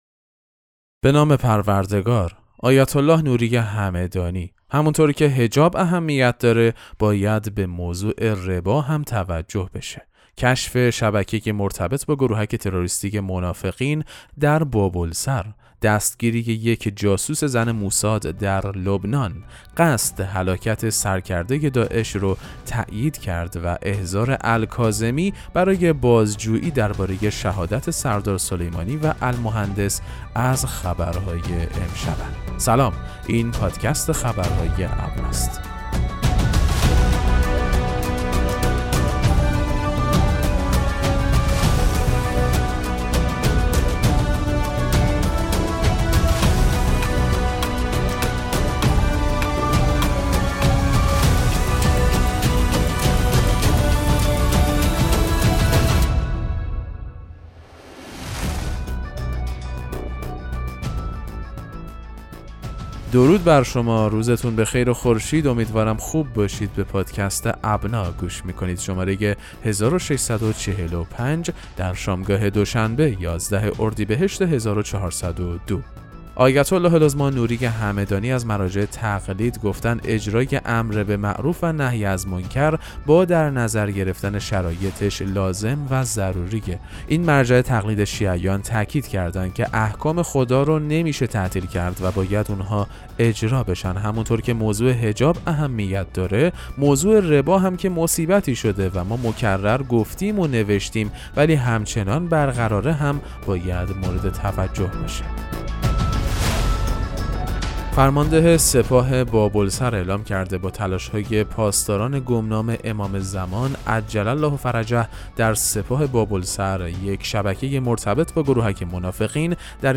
پادکست مهم‌ترین اخبار ابنا فارسی ــ 11 اردیبهشت 1402